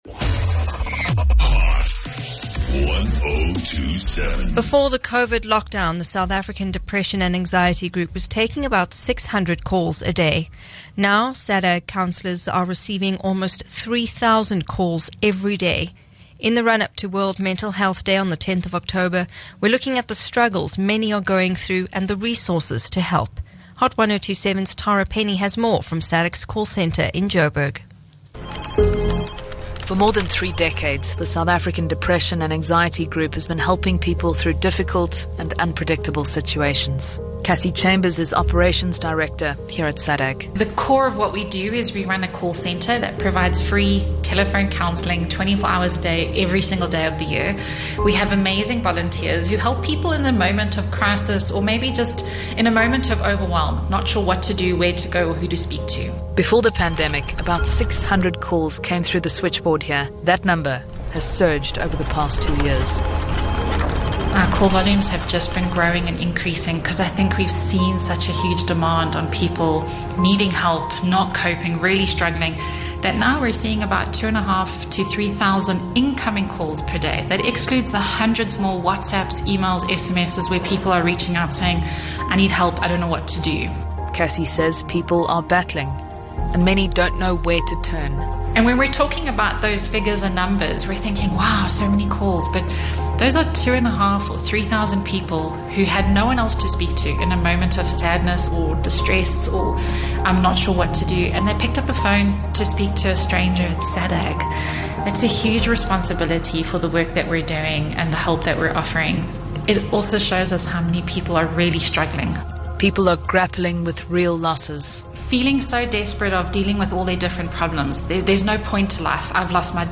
SADAG’s call center in Joburg…